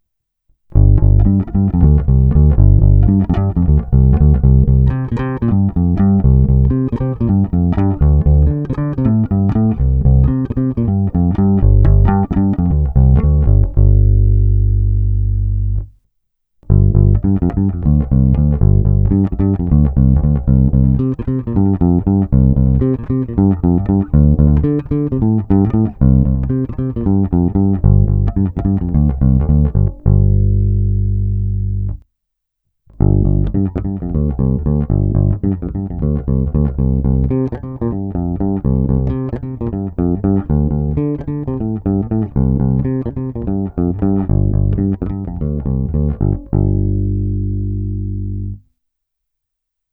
Zvuk je tučný, na jeho pozadí i přes tupé struny slyším zvonivé vrčení typické právě pro modely 1957-1959.
Není-li řečeno jinak, následující nahrávky jsou provedeny rovnou do zvukové karty a kromě normalizace ponechány bez úprav. Tónová clona vždy plně otevřená.